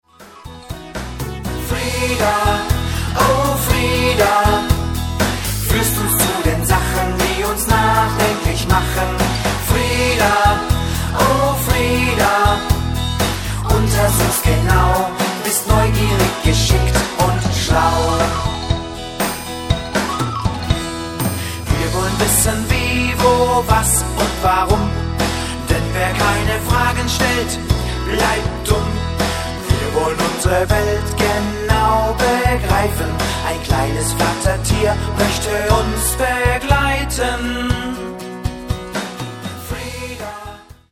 (vocal und playback)